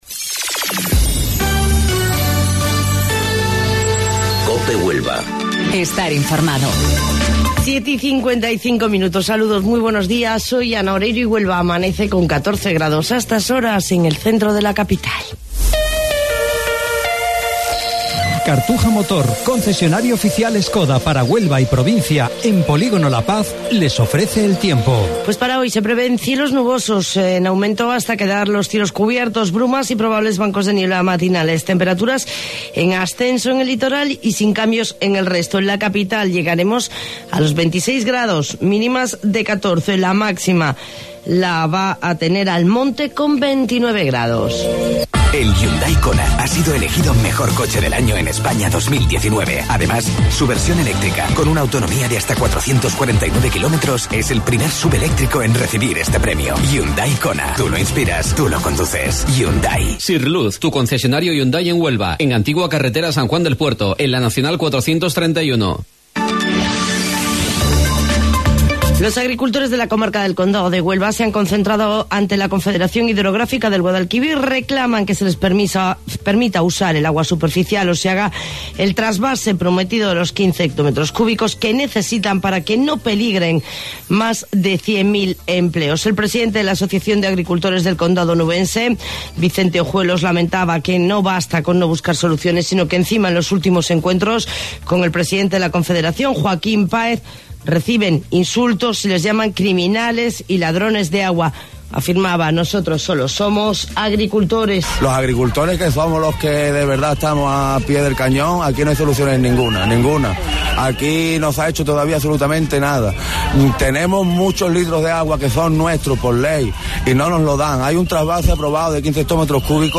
AUDIO: Informativo Local 07:55 del 7 de Mayo